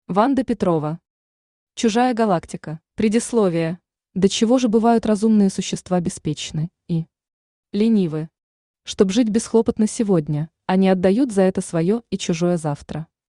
Аудиокнига Чужая галактика | Библиотека аудиокниг
Aудиокнига Чужая галактика Автор Ванда Михайловна Петрова Читает аудиокнигу Авточтец ЛитРес.